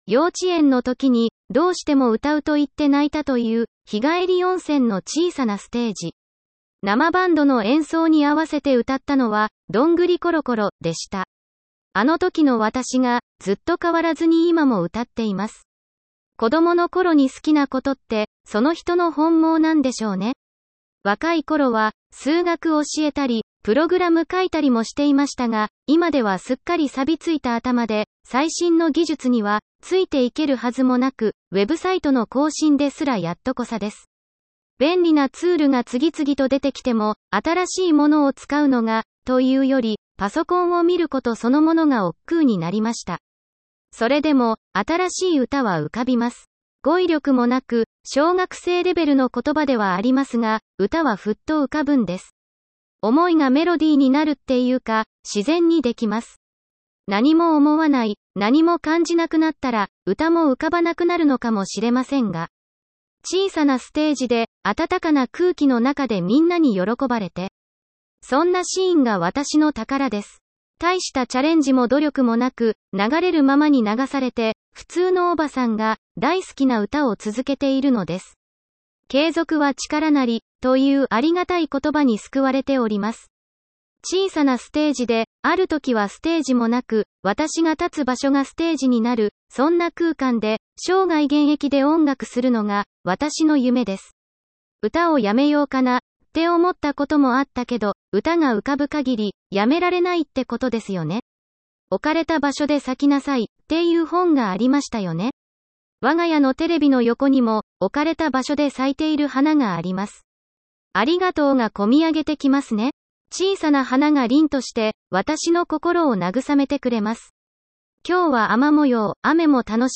音読ソフト使ってみました！！
昨日書いたblogを、音読ソフト、音読さんに読んでもらいました！！
え～～、ところどころ平坦なイントネーションはありますが、意味は通じますので